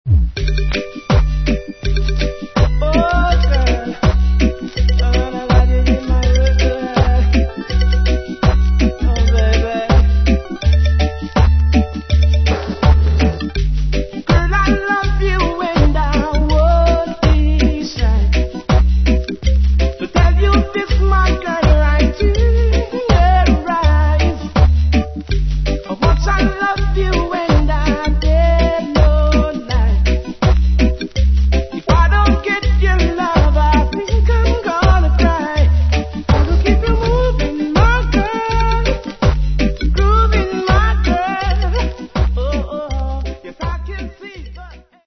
TOP 10 DANCEHALL